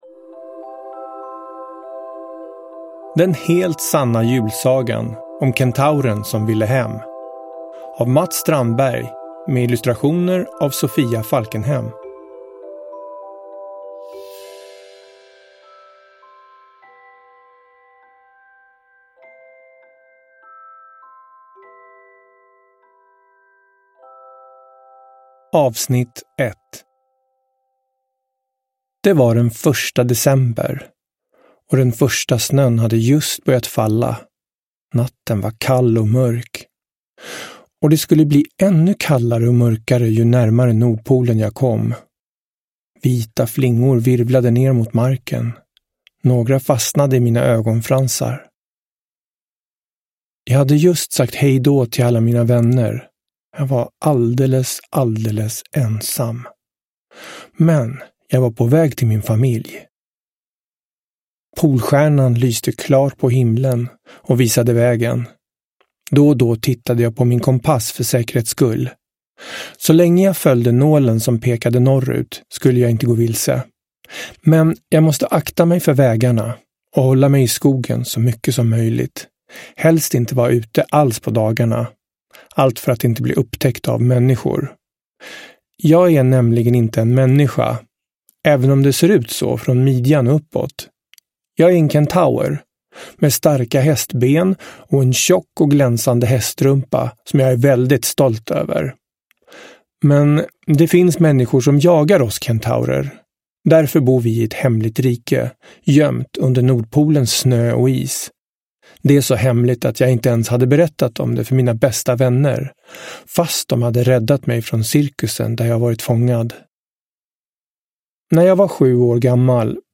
Den helt sanna julsagan om kentauren som ville hem – Ljudbok – Laddas ner